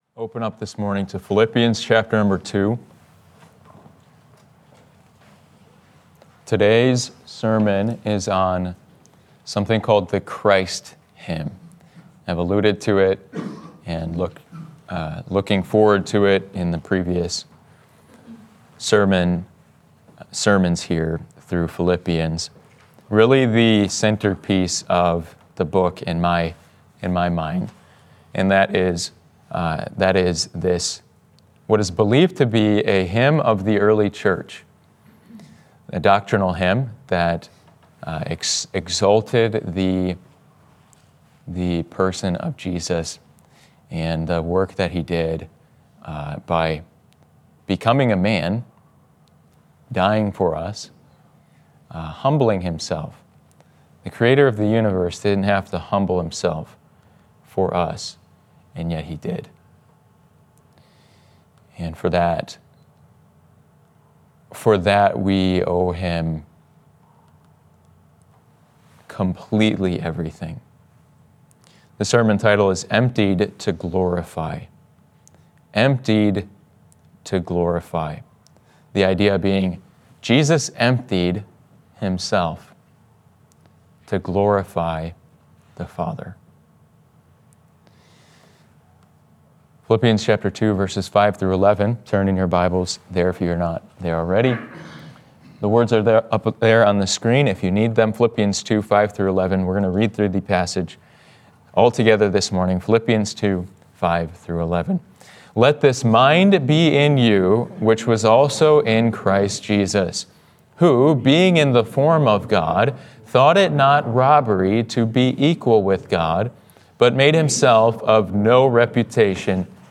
Emptied to Glorify —Sunday AM Service— Passage